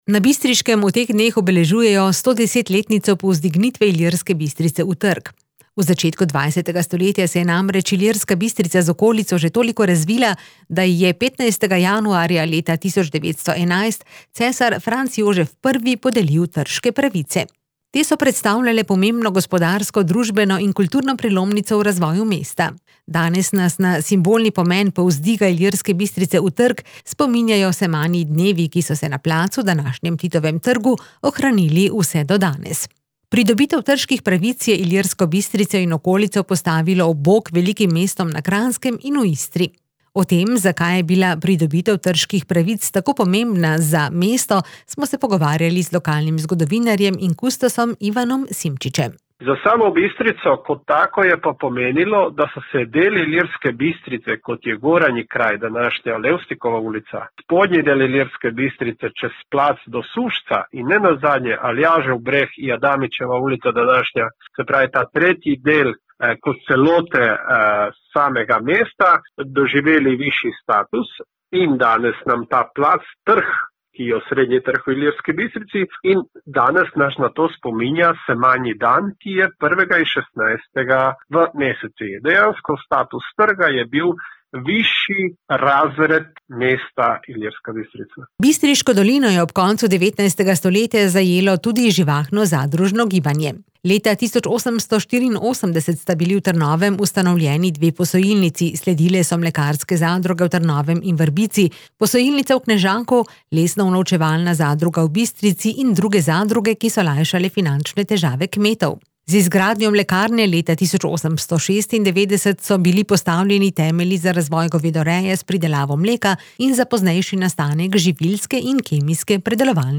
kr21-ilirska-bistrica-je-bila-pred-110-leti-iz-vasi-povisana-v-trg-komplet.mp3